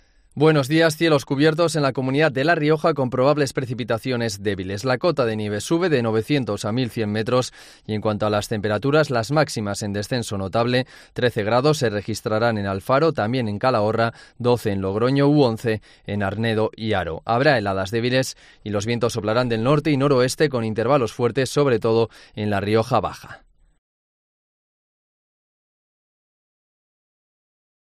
AUDIO. Pronóstico en La Rioja: